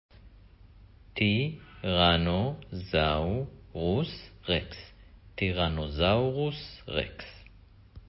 טי-רנו-זאו-רוס רקס
T-Rex-name.mp3